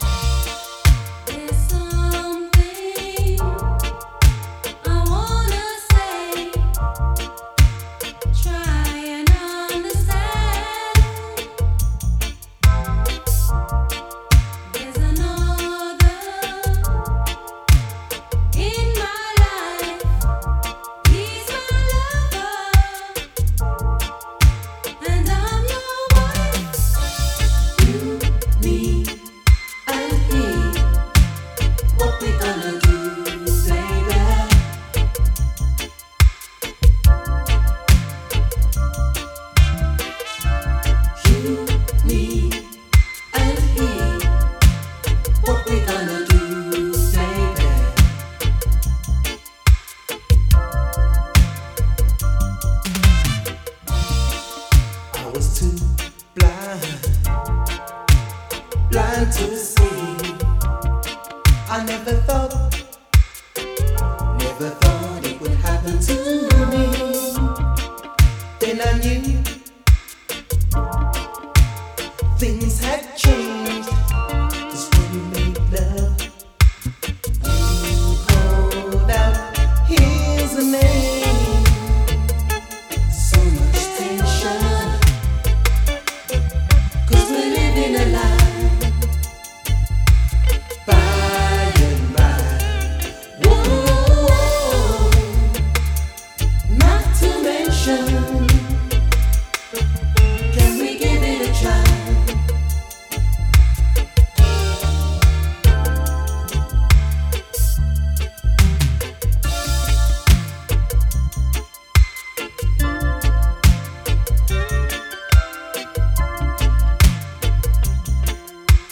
This blissful example of Lover’s Rock
steeped in a soulful, Pop sensibility.